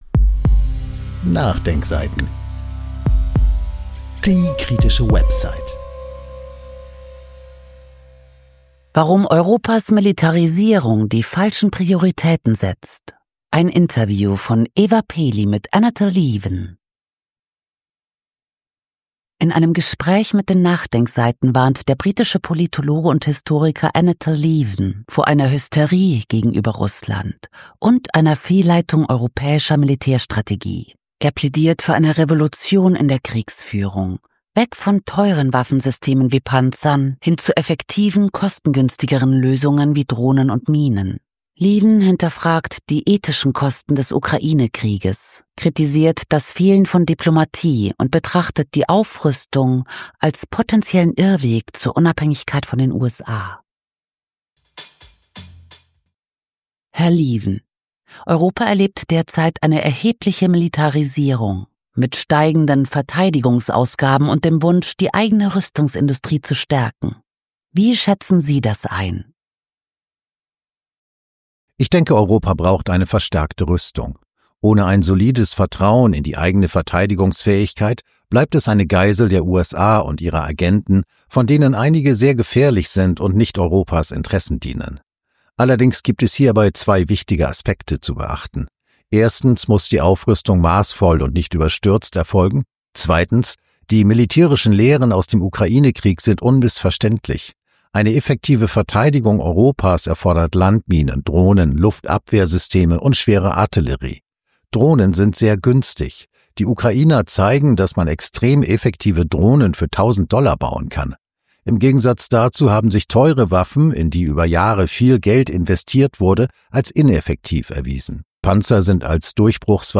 In einem Gespräch mit den NachDenkSeiten warnt der britische Politologe und Historiker Anatol Lieven vor einer „Hysterie“ gegenüber Russland und einer Fehlleitung der europäischen Militärstrategie. Er plädiert für eine Revolution in der Kriegsführung: weg von teuren Waffensystemen wie Panzern hin zu effektiven, kostengünstigeren Lösungen wie Drohnen und Minen.